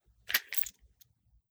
38 SPL Revolver - Unloading 002.wav